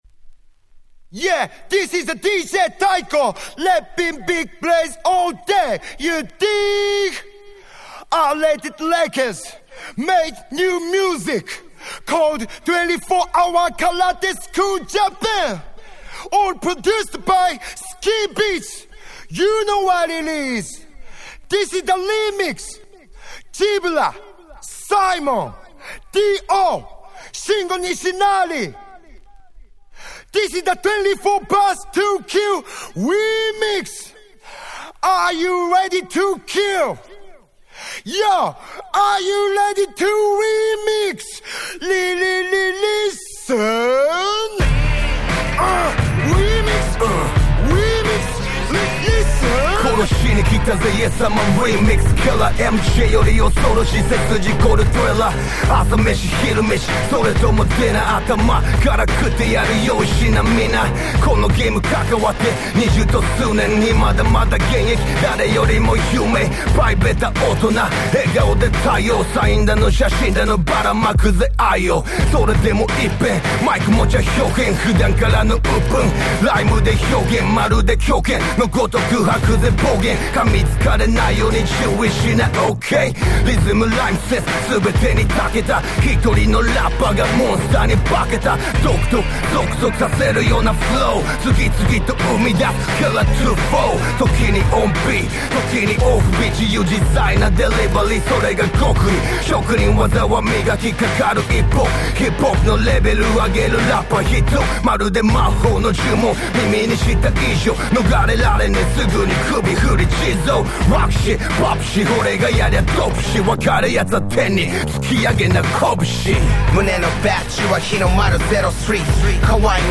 10' Super Nice Japanese Hip Hop !!